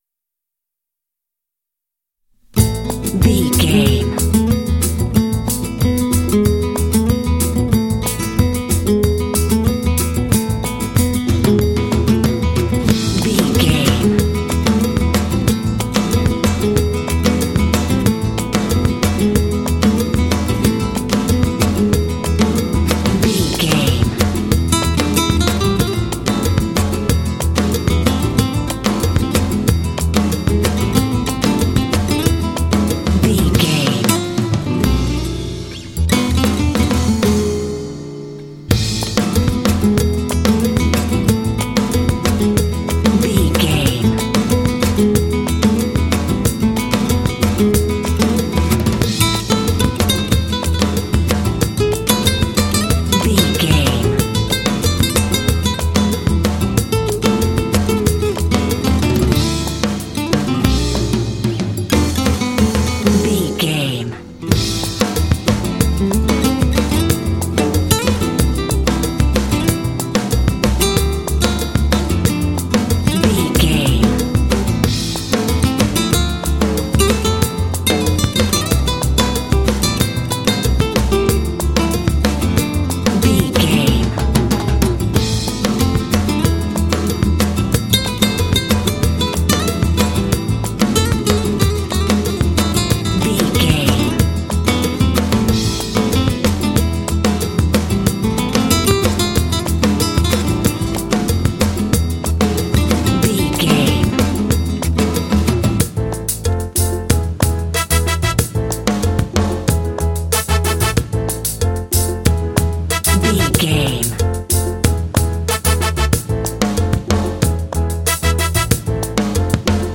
Uplifting
Aeolian/Minor
smooth
percussion
acoustic guitar
bass guitar
drums
conga
piano
brass
latin
Lounge
downtempo